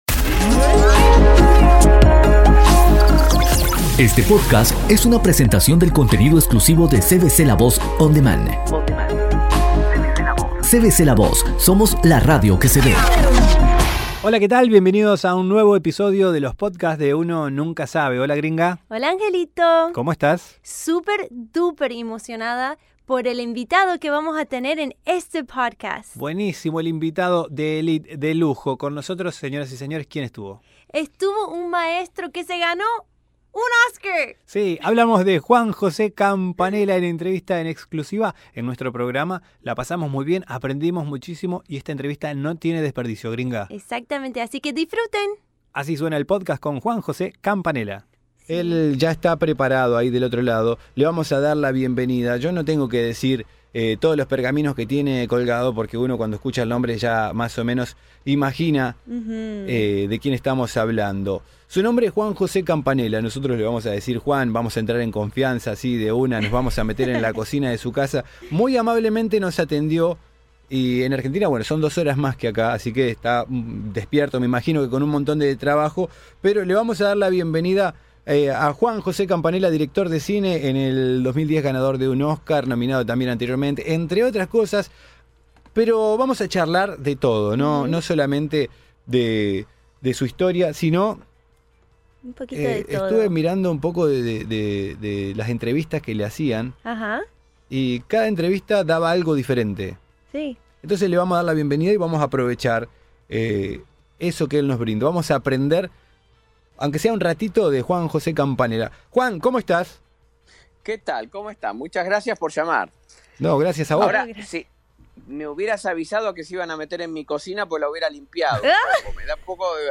Entrevista a Juan José Campanella
El reconocido director de cine y ganador de un Oscar,Juan Jose Campanella hablo en Uno Nunca Sabe.